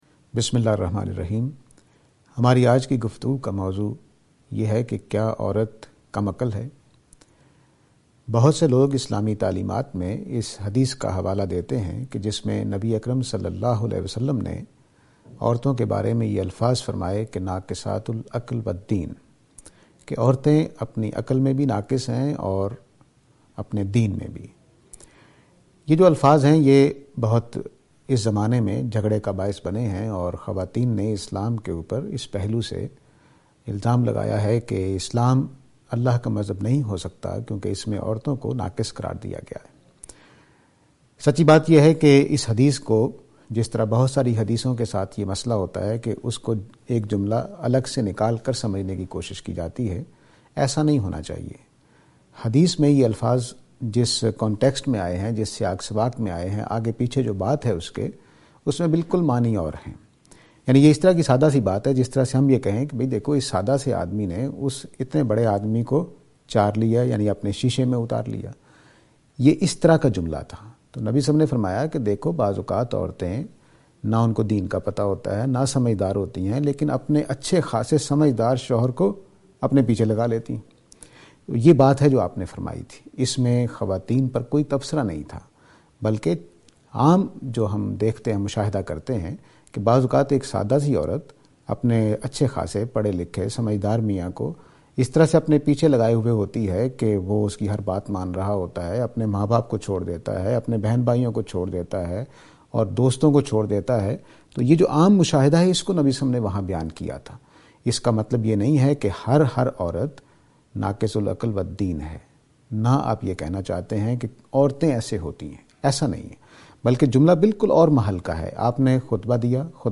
This lecture is and attempt to answer the question "Are Women silly?".